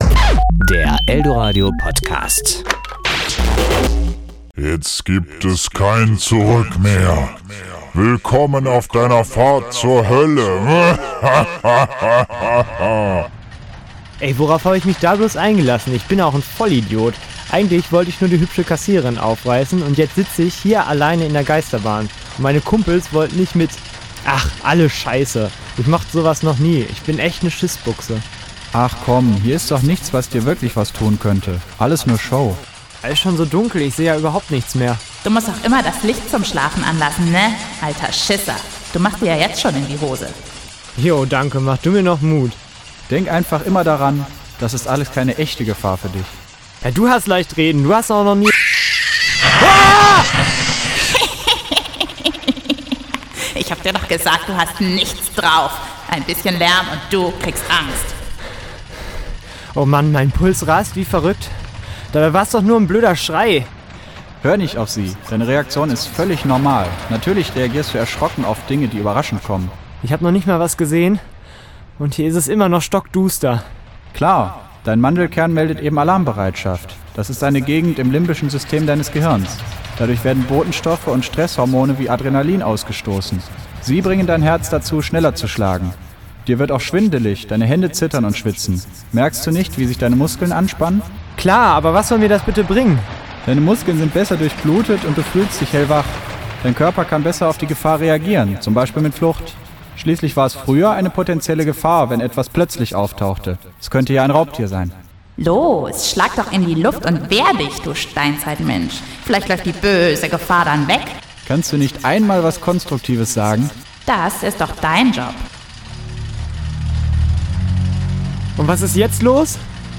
Hörspiel: -Angst-